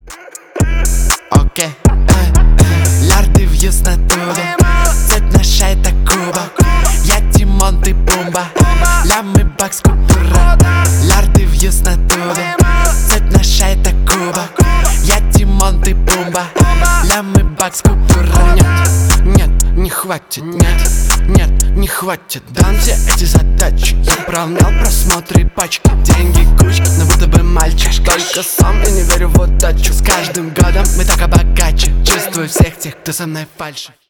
Stereo
Рэп и Хип Хоп